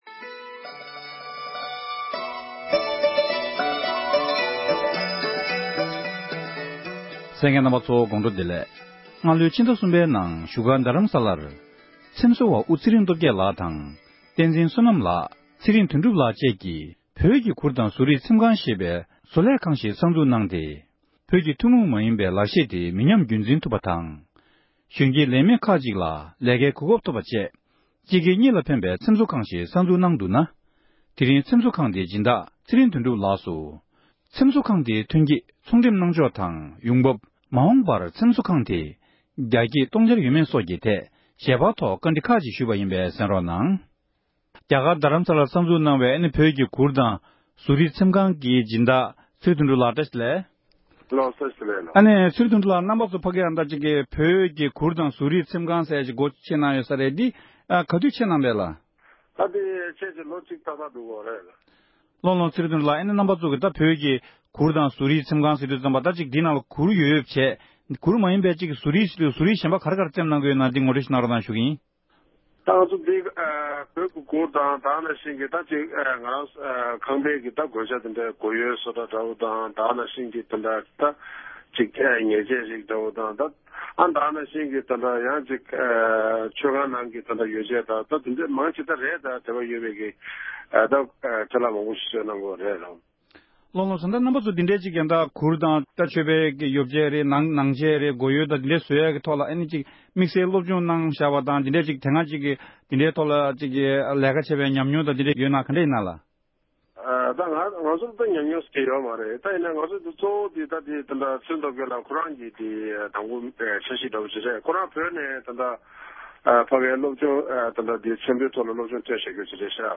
ཚོང་ལས་དེ་དང་འབྲེལ་བའི་སྐོར་བཀའ་འདྲི་ཞུས་ཞིག་གསན་རོགས༎